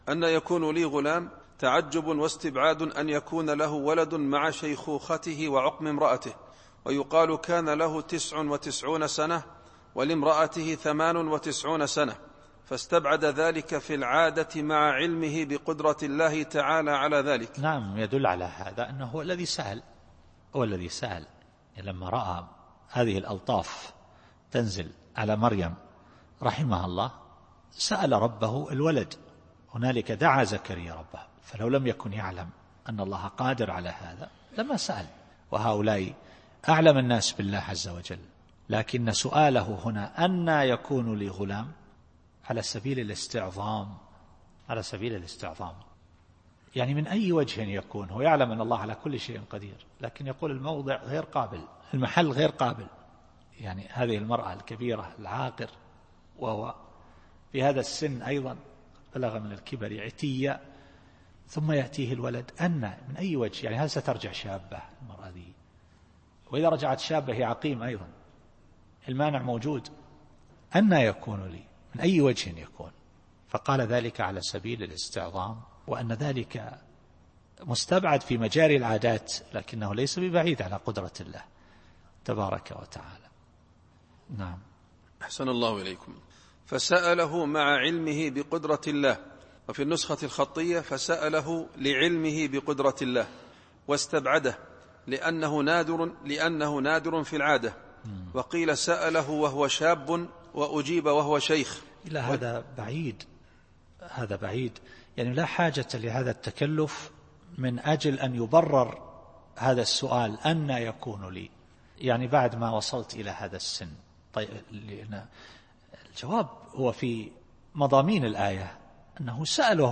التفسير الصوتي [آل عمران / 40]